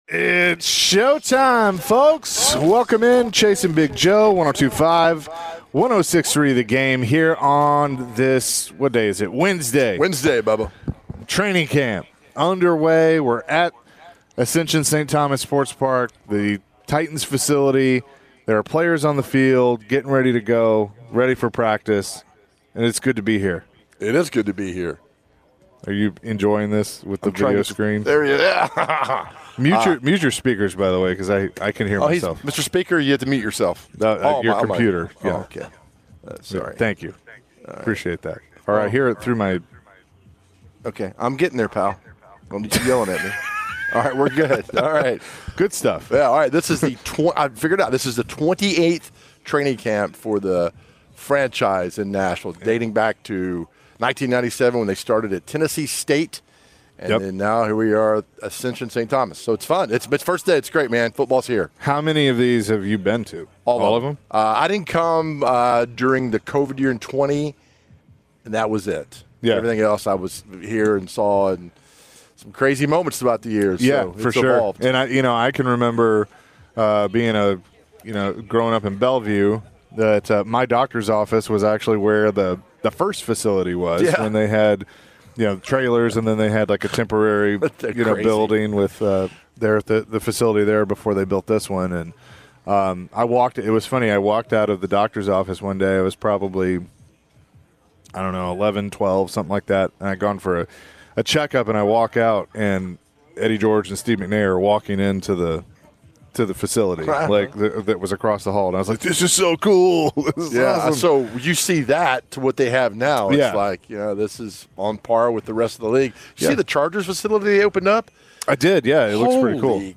the guys were at St. Thomas Sports Park